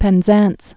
(pĕn-zăns)